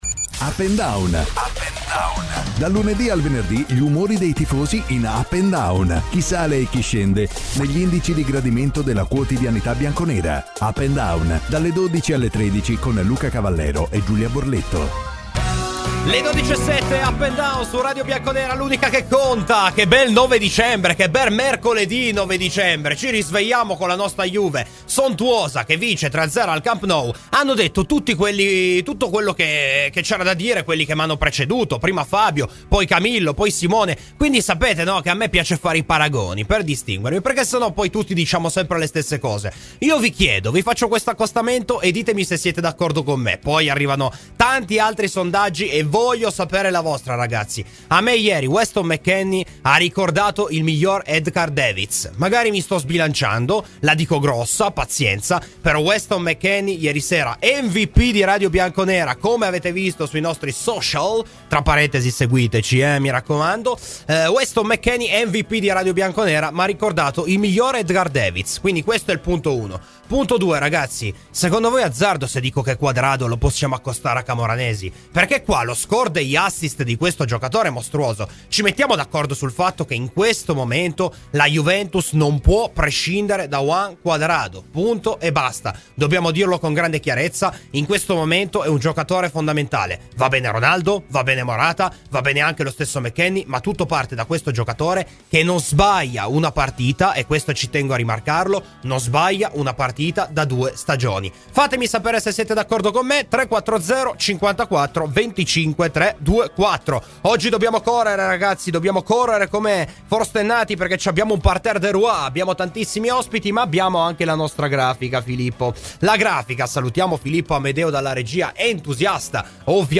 Clicca sul podcast in calce per l’intervista integrale e la trasmissione integrale.